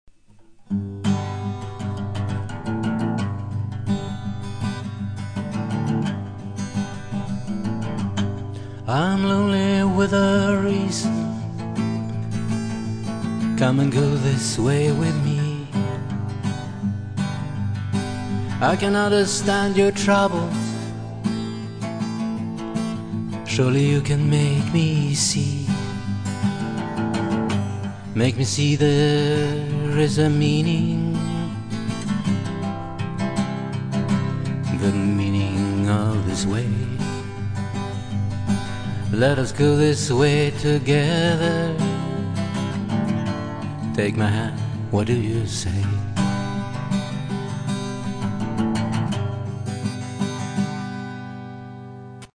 gitarrlåt (mest på skoj).